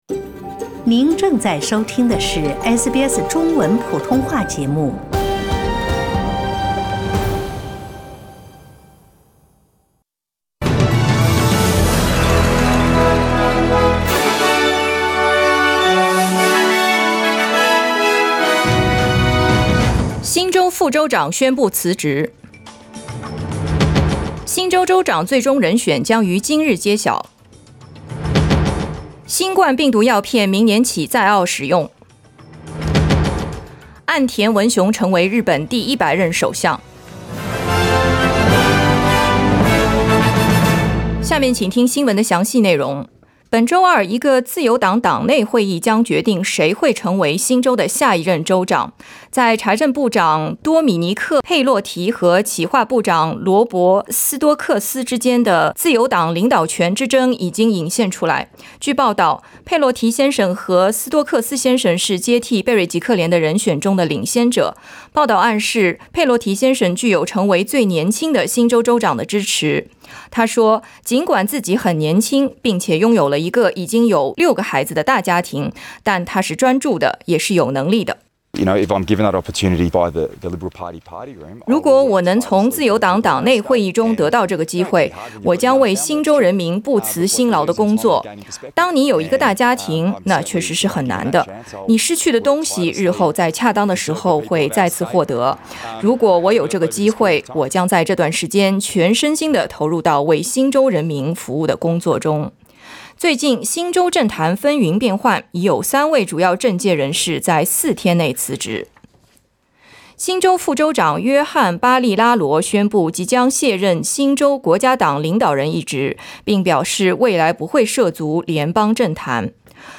SBS早新聞（2021年10月5日）
SBS Mandarin morning news Source: Getty Images